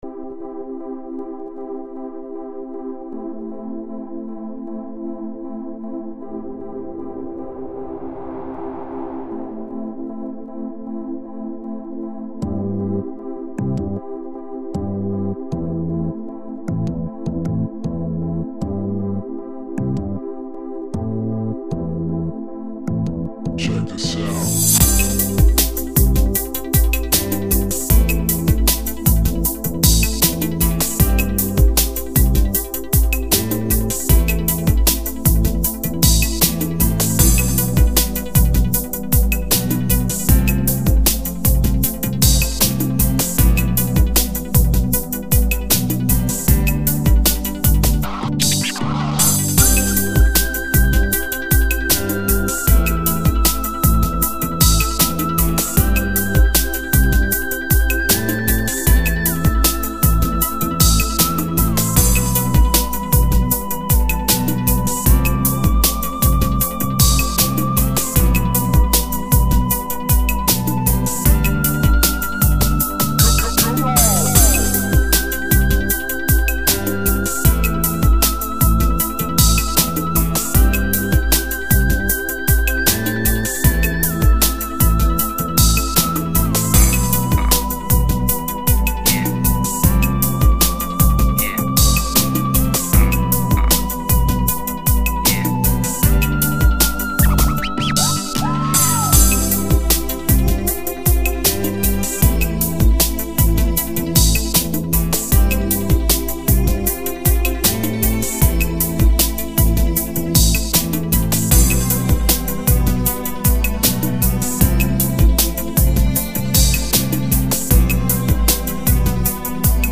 dance/electronic
classic and quite warm melody
i re done it again with some vst plugins
Ambient
RnB
Eighties/synthpop